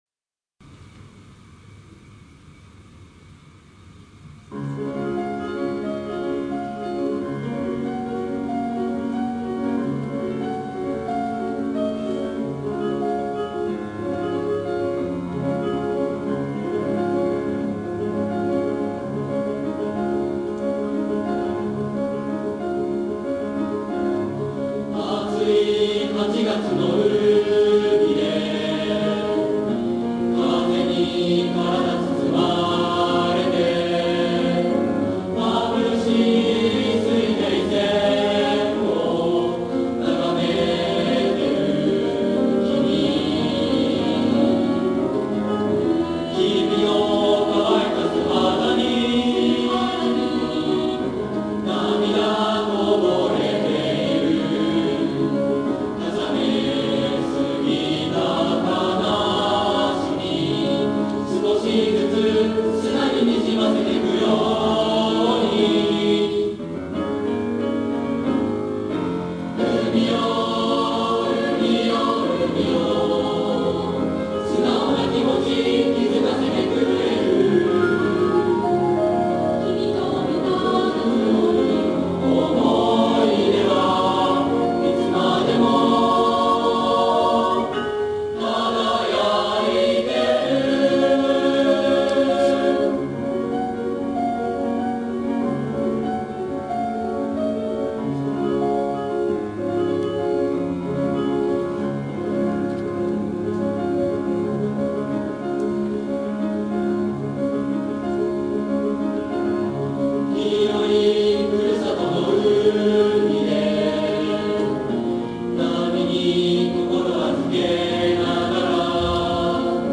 ～校内合唱コンクールが行われました（11月12日）～
芸術の秋，この日のために朝夕となく練習を重ねてきた中学生・高校生の歌声が，学内に響きわたりました。